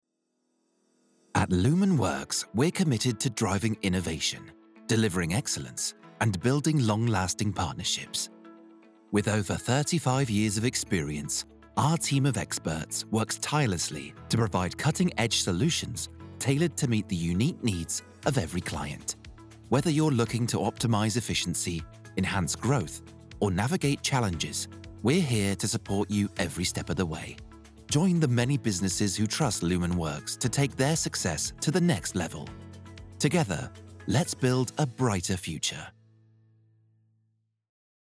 British Voice Over Artist
Utilising a professional home studio setup, I am able to provide not only fast deliverables, but clean and high quality audio.
Corporate Demo